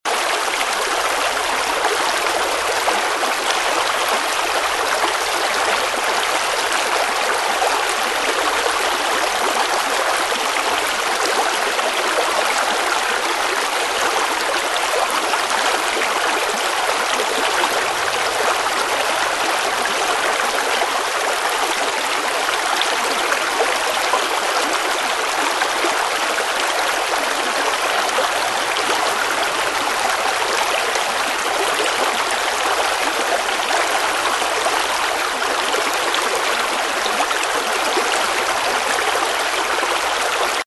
Звуки реки
Природная река средних размеров с журчанием